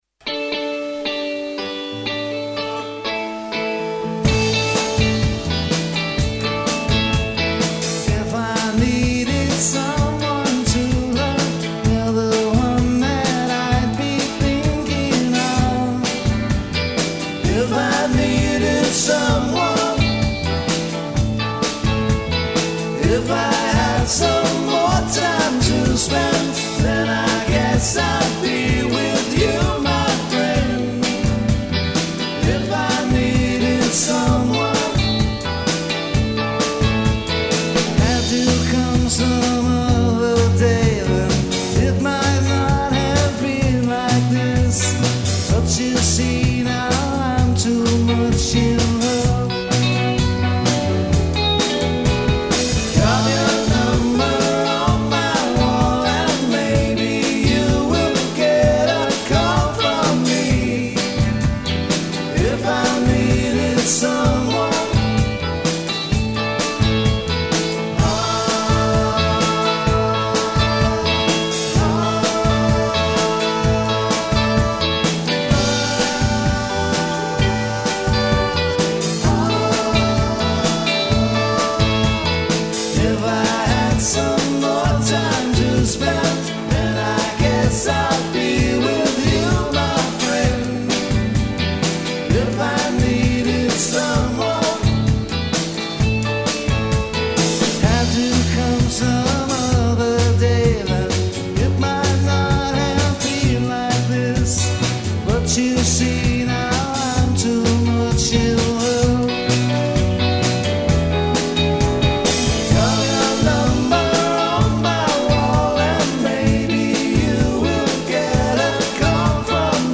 lLive recording-